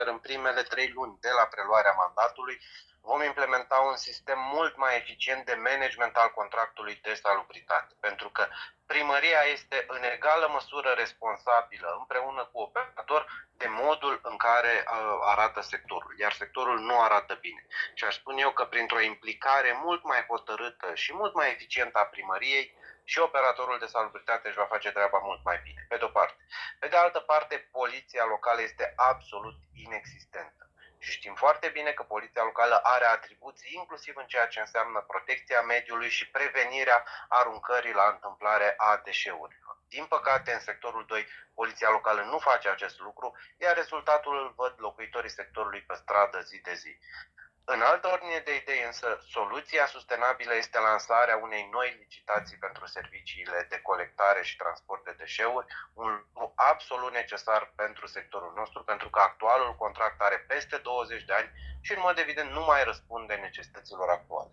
După câștigarea alegerilor, Rareș Hopincă anunța, la București FM, lansarea unei licitații pentru desemnarea unui operator al serviciului public de salubritate, dar și măsuri imediate, pentru îmbunătățirea stării de curățenie din Sectorul 2, în primele trei luni de mandat.